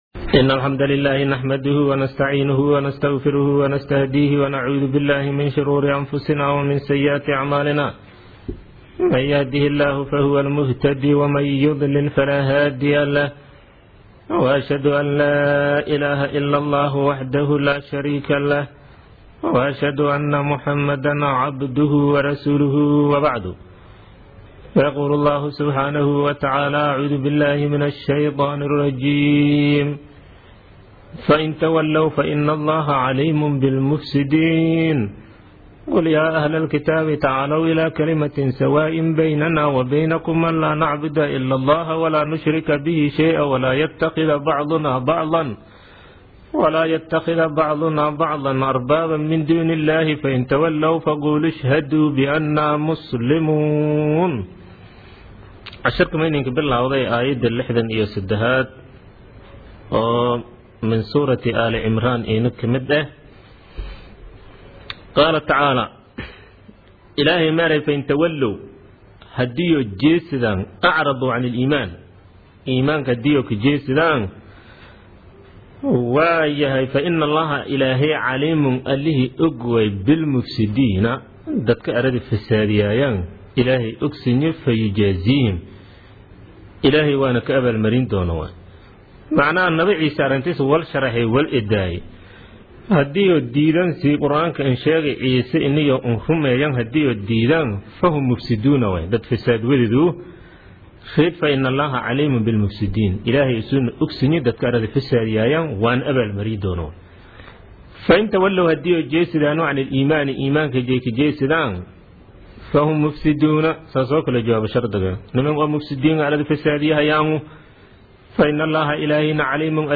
Casharka Tafsiirka Maay 45aad